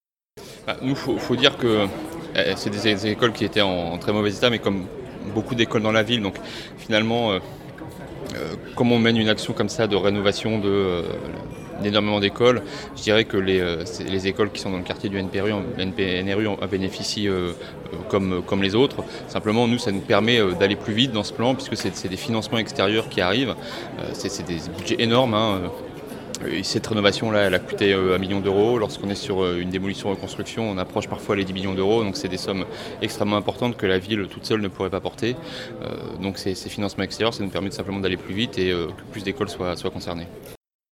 Franck Gagnaire, adjoint à l’éducation